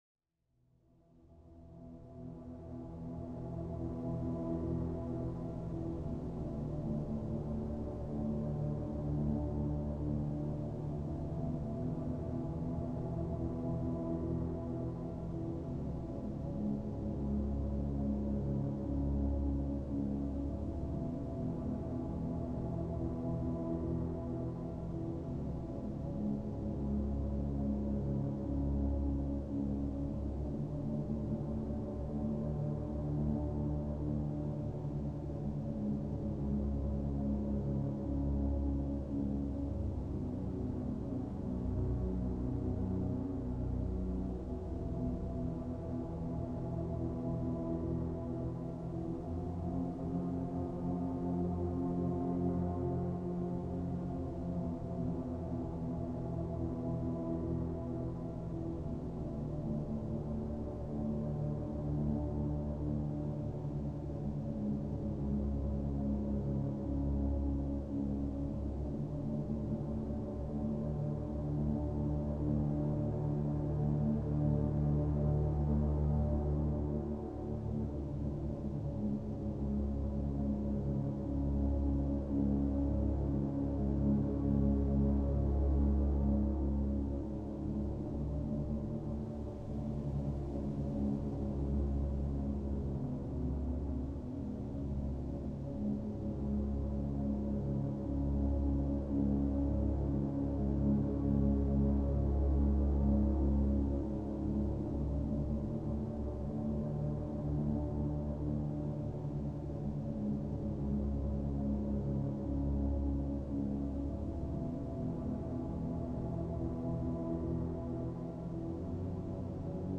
occasional ambient music releases